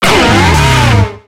Cri d'Incisache dans Pokémon X et Y.